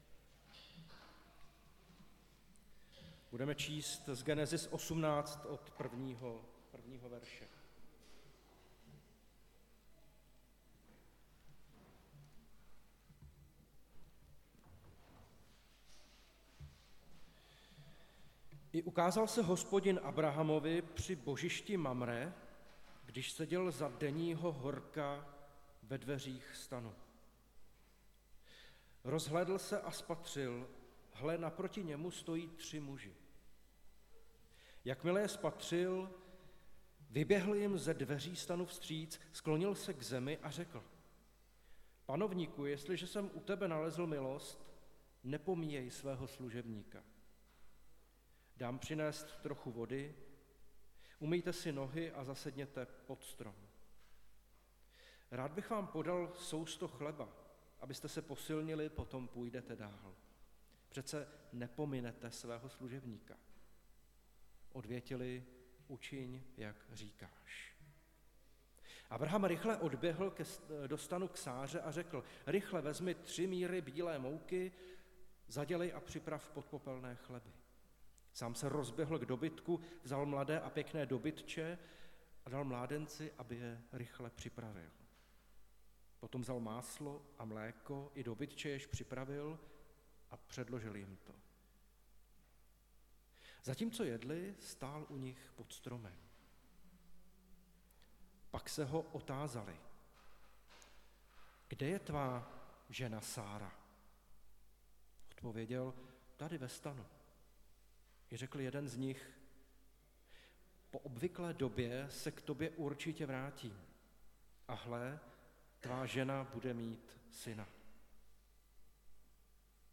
Nedělní kázání – 30.10.2022 Abrahamovi hosté